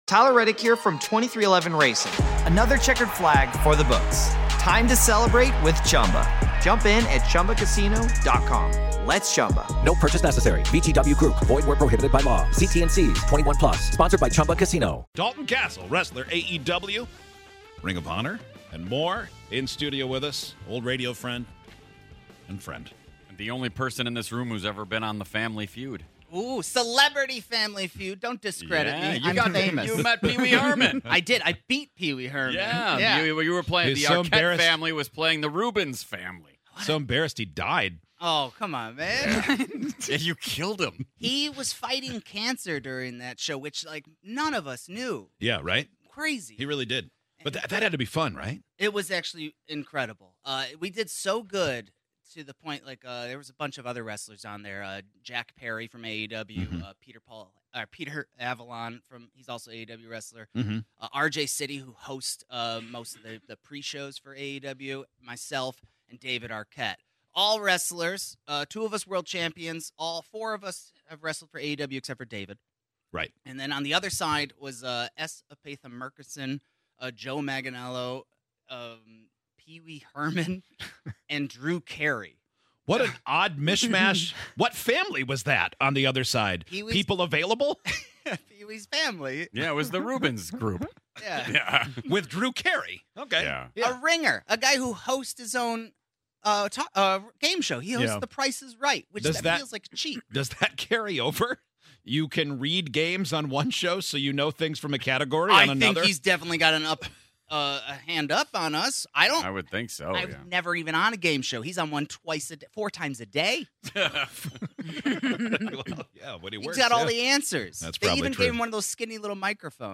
On today's show, our favorite wrestler Dalton Castle joined us in studio today.